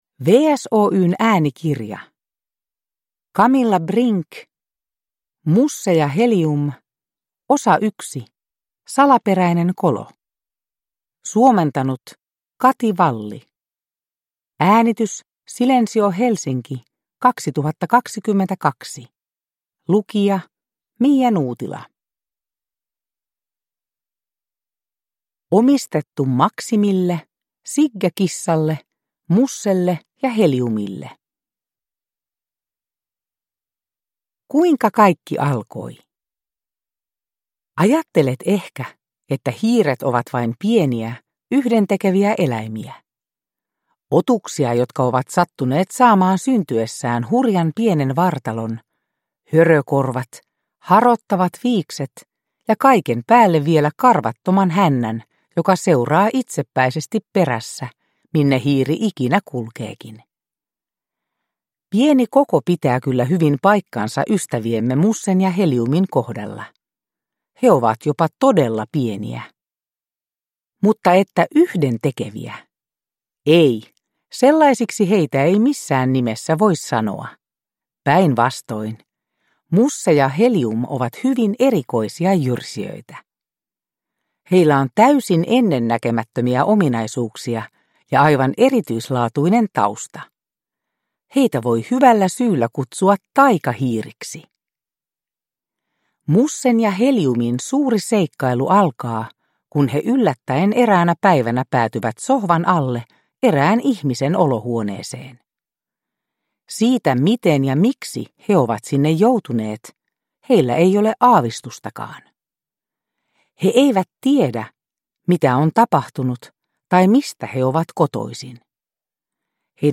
Musse ja Helium 1: Salaperäinen kolo – Ljudbok – Laddas ner